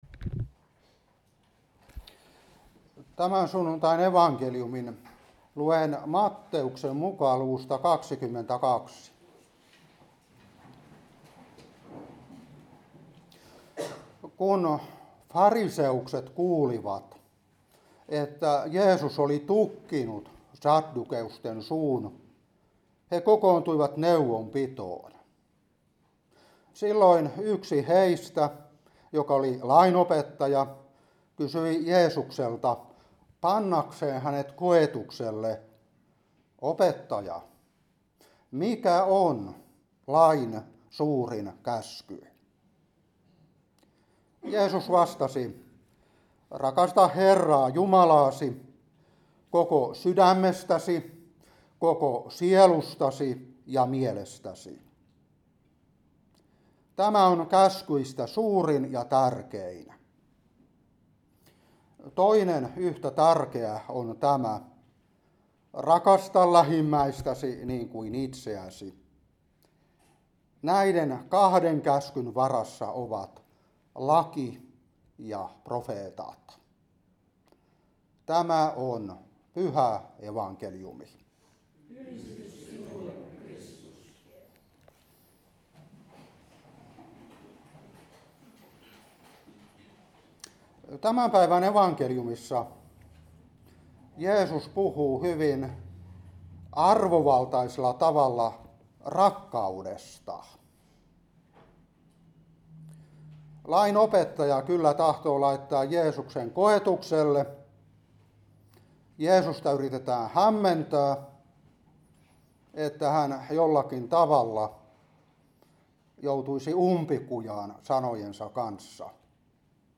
Saarna 2025-10. Matt.22:34-40.